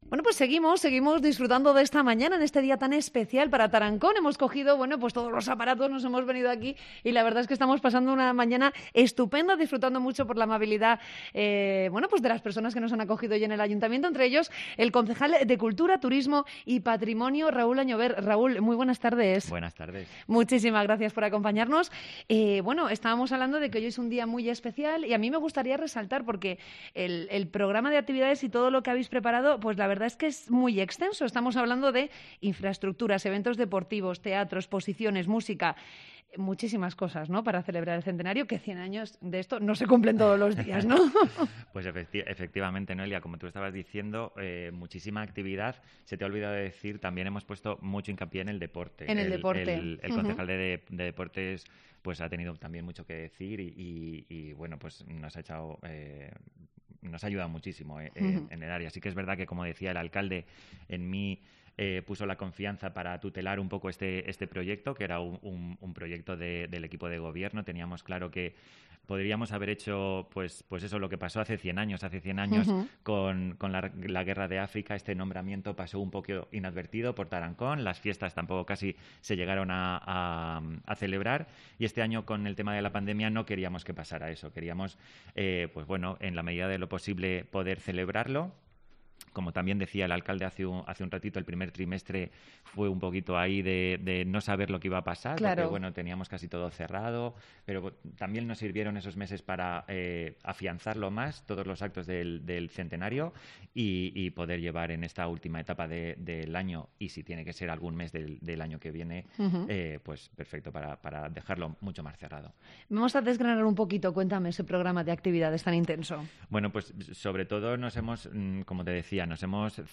AUDIO: Entrevista con el concejal de Turismo, Cultura y Patrimonio de Tarancón, Raúl Añover